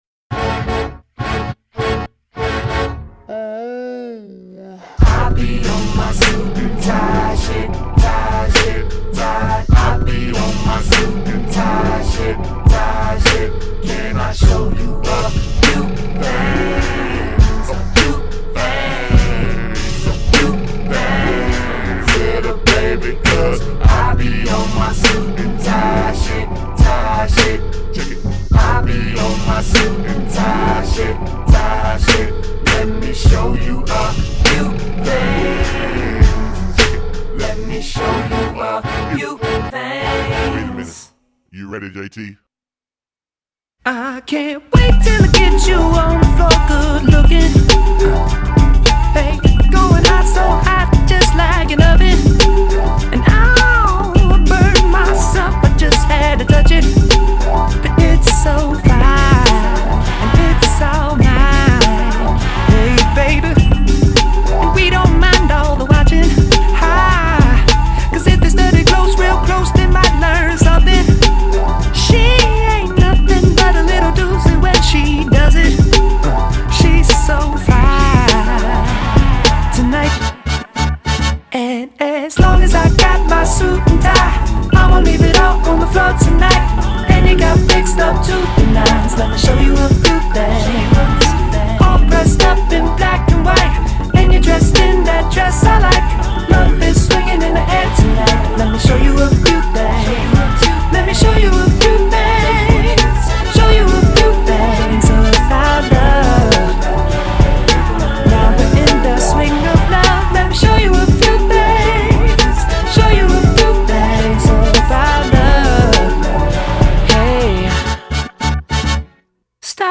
laced intro, throwback R&B hook
Robin Thicke-esque vocals